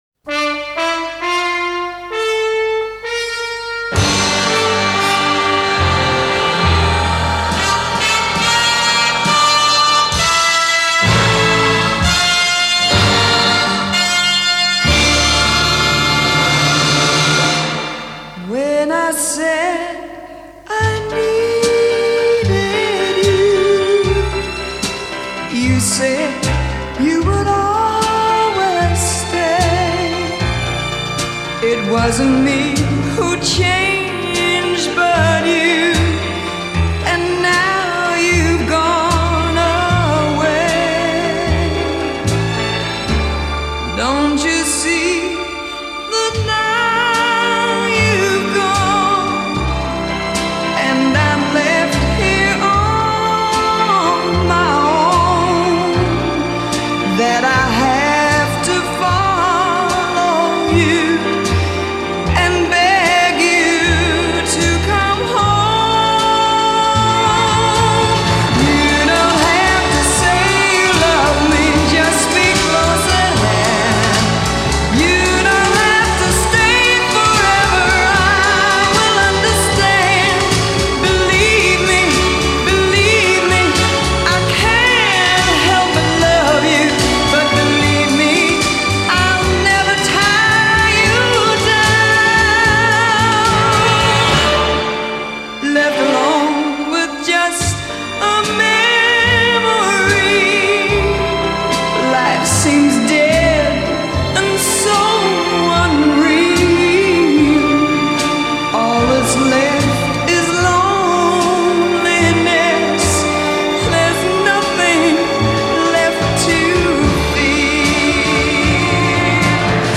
stavolta cantata in inglese
da un’interprete femminile